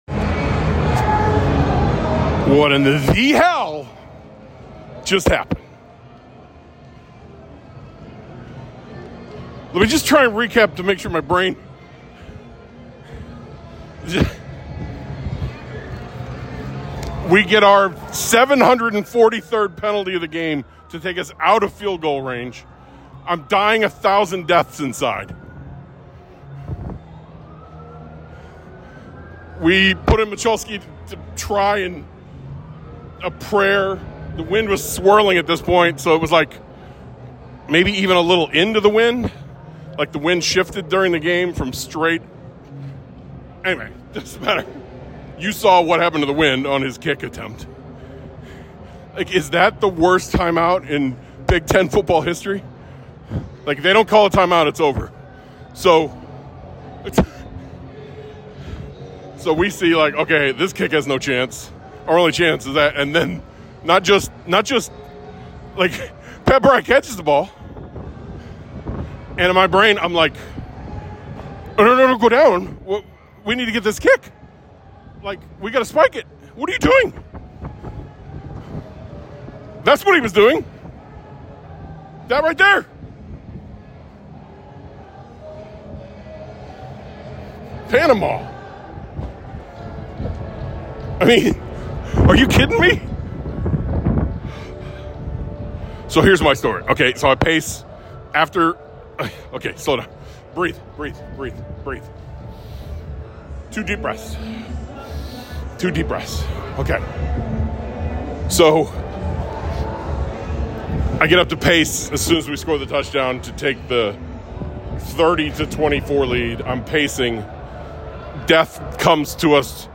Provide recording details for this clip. From The Stands - Rutgers 2024 So I recorded this one (very short) immediately after the final horn.